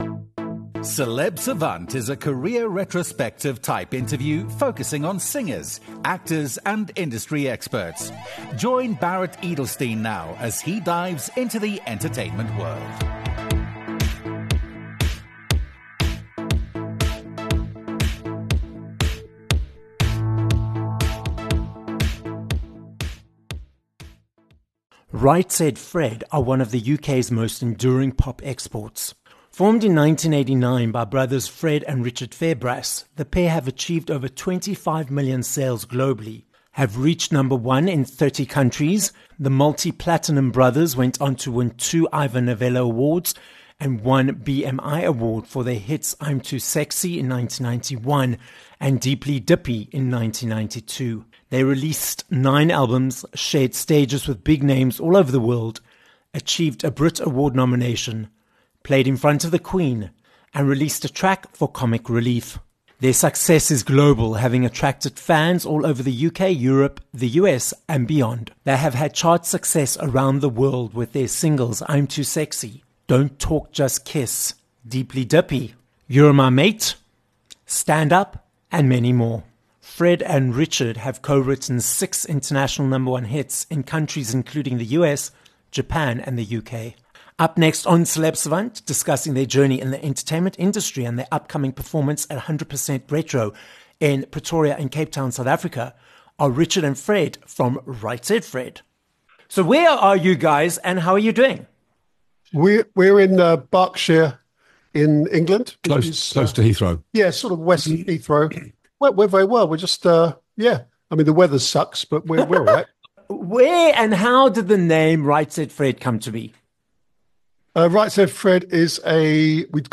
Right Said Fred - comprised of singer and songwriter duo, Richard and Fred Fairbrass - join us on this episode of Celeb Savant. We hear about their more than three decades in the industry, achieving sales of over 25 million globally, their breakout hit 'I'm Too Sexy', collaborating with Drake and Taylor Swift, and their upcoming performances in South Africa at the 100% RETRO Festival.